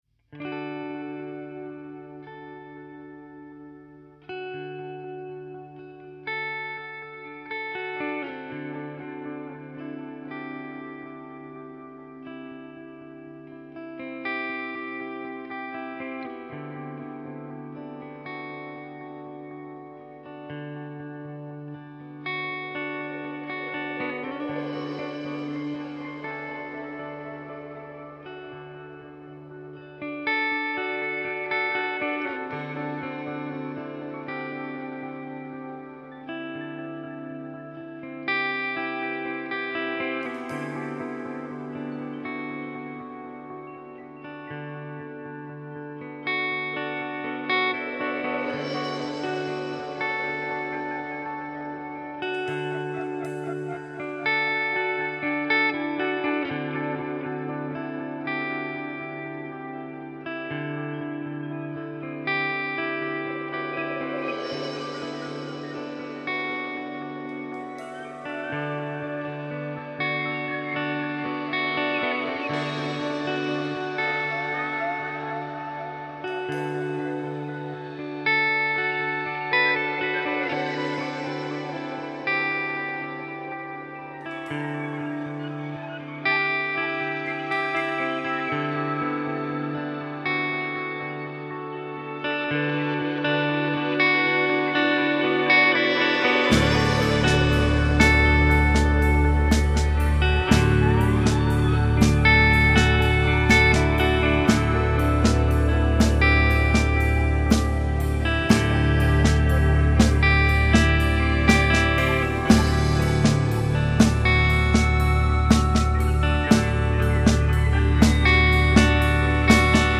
Italian Band